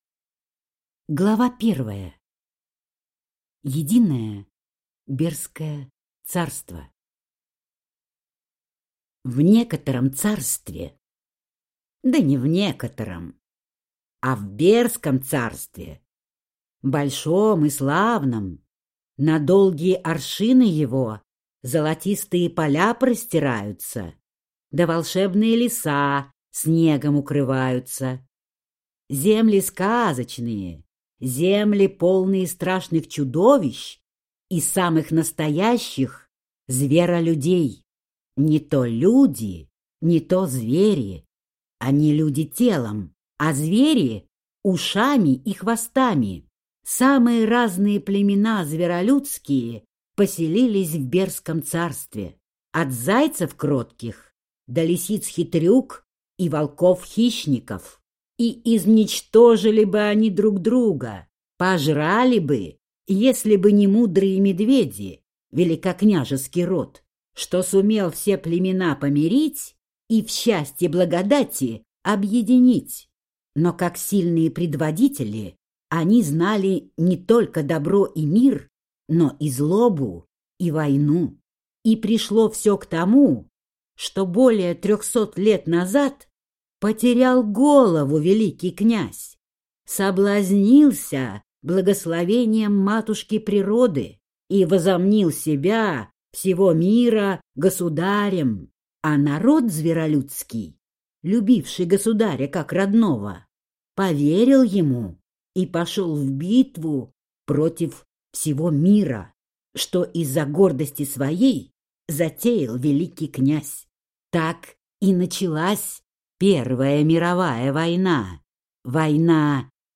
Аудиокнига Вперед, за Фениксом! Возродим Мир из Пепла | Библиотека аудиокниг